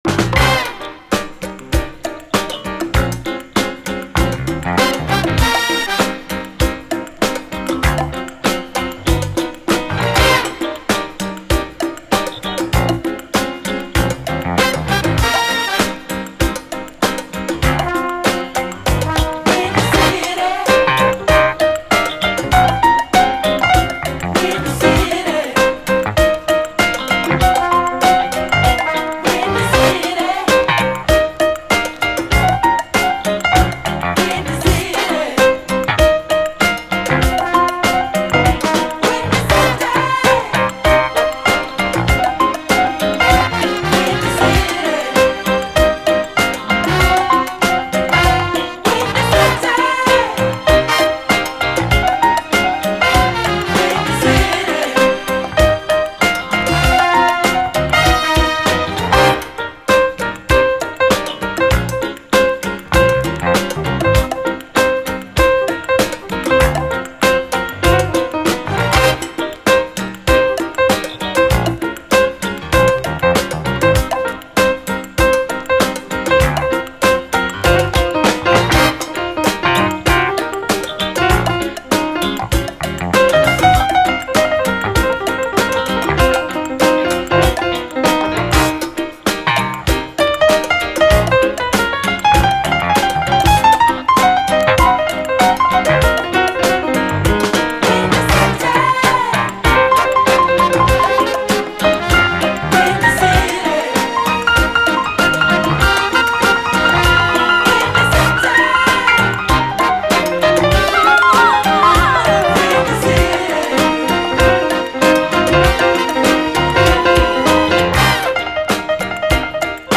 SOUL, JAZZ FUNK / SOUL JAZZ, 70's～ SOUL, JAZZ, 7INCH
ドラマティックかつ切れ味鋭いピアノ＆ブラス＆コーラスなど、キリッと締まったエレガント・ステッパー！